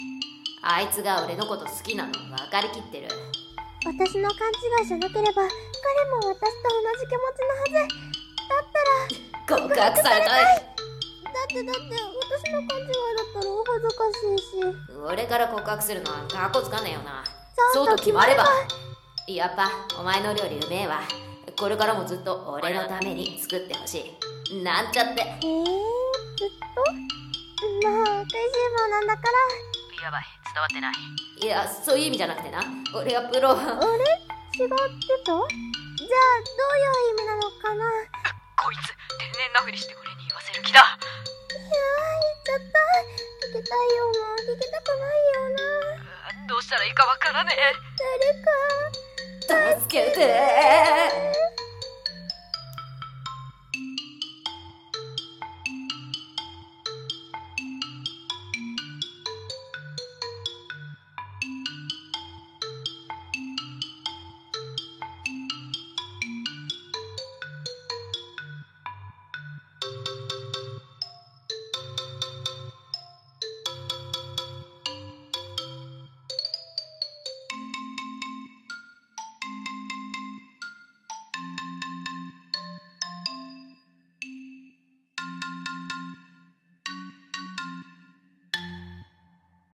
コラボ済／声劇台本